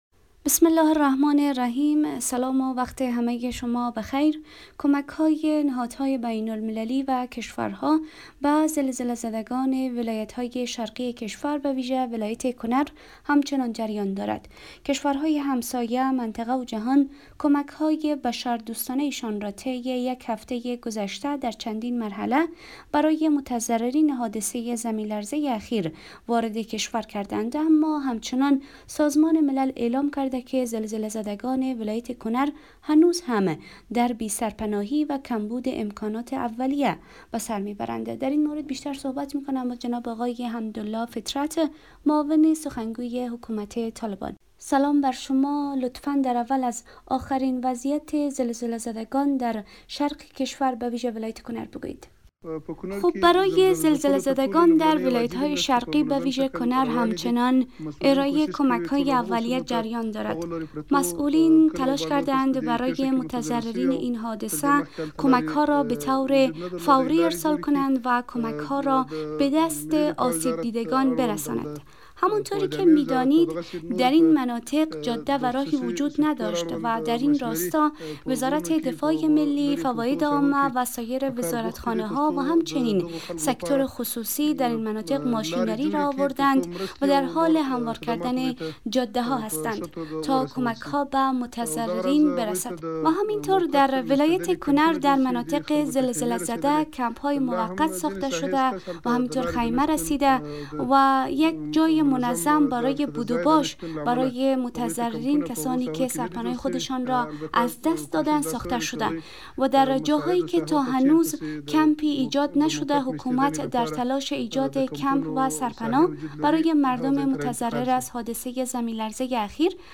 معاون سخنگوی حکومت طالبان در مصاحبه با رادیو دری از ادامه کمک رسانی به زلزله زدگان شرق افغانستان خبر داد.
مصاحبه